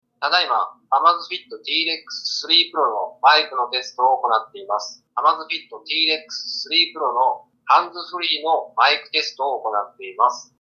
▼Amazfit  T-Rex 3 Proのマイクを使って電話を掛けた通話品質はこちら▼